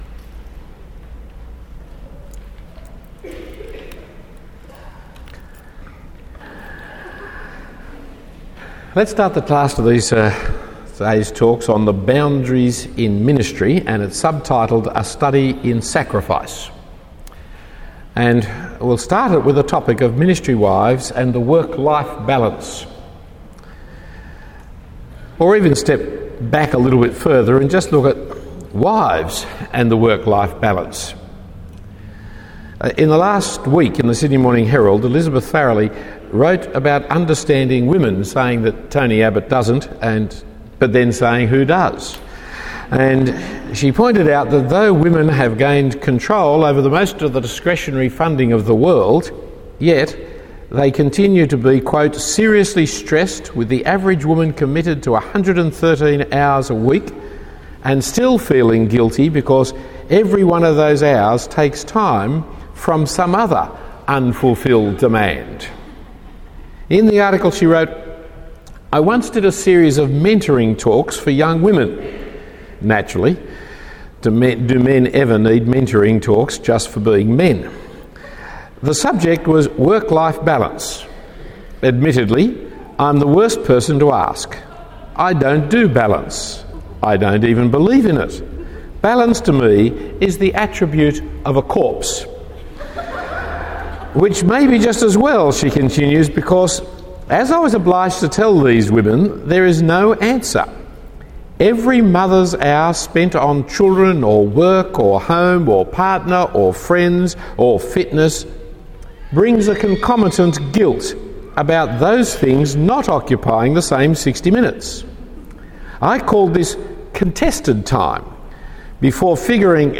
Talk 2 of 2 given at the Ministry Wives Conference on Boundaries.